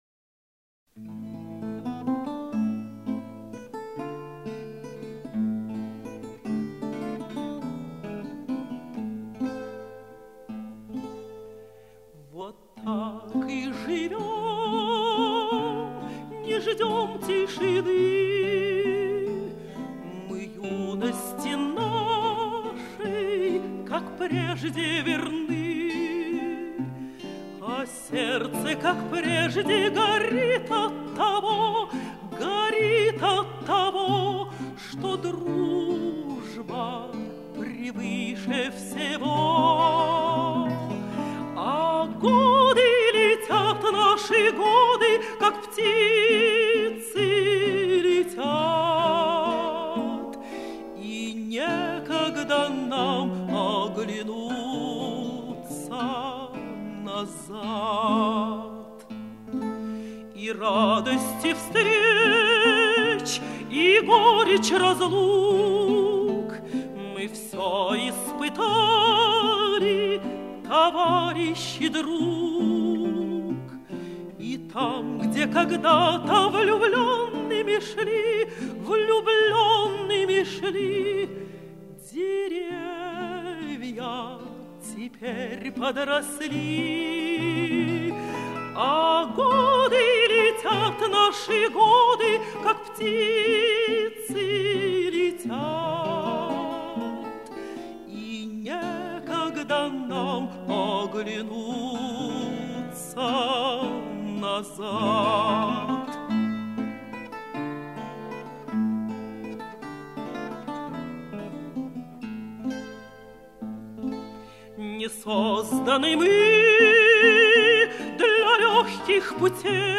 13 августа. День рождения выдающейся русской певицы, Народной артистки РСФСР Валентины Левко